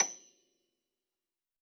53r-pno28-F6.wav